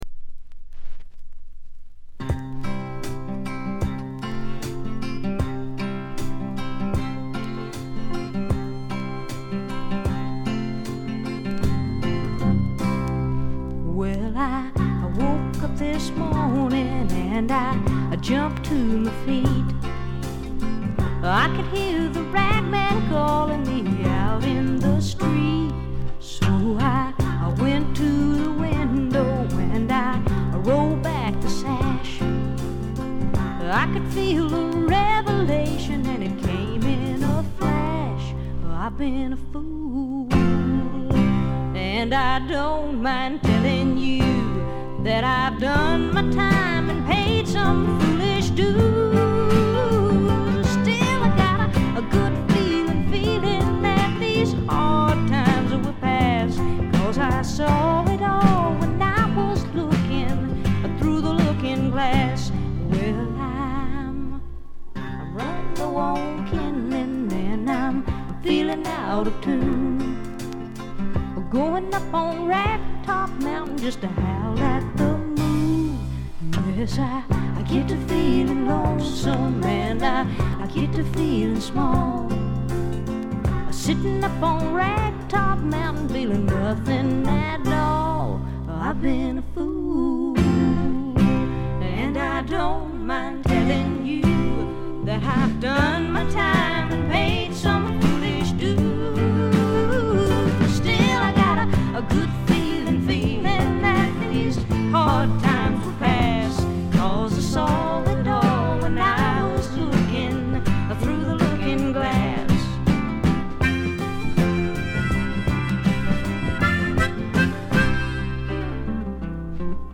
バックグラウンドノイズがほぼ常時出ており静音部でやや目立ちます。
試聴曲は現品からの取り込み音源です。
Guitar, Harmonica, Vocals